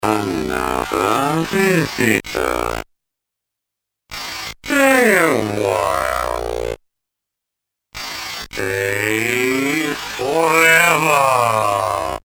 editSPEECH PROCESSED BY OTHER MACHINE I recorded some fun variations about the "another visitor" speech processed by different samplers, effects, vocoders or whatever!
time stretch Akai S2000